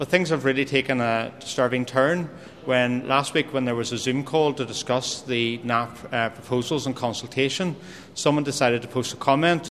Minister Andrew Muir says the threat came after discussions about a plan which aims to reduce water pollution in the North: